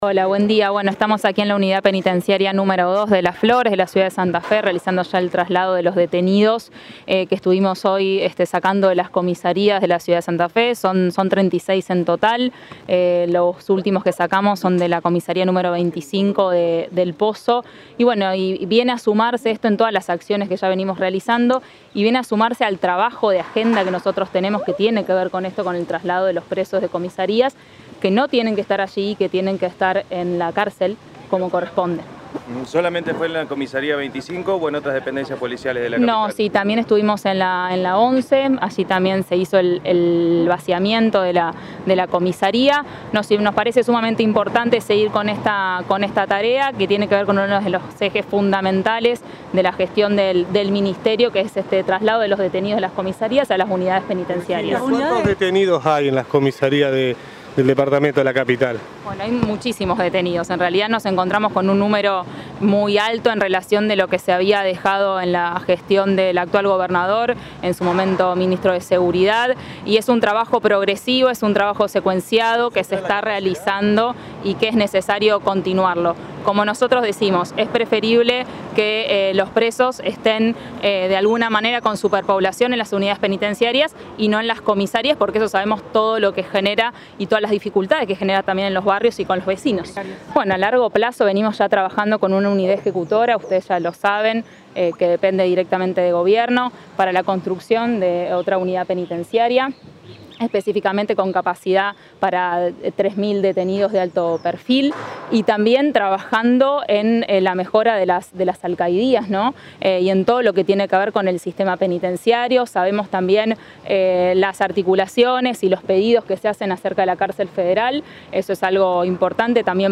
Declaraciones de Virginia Coudannes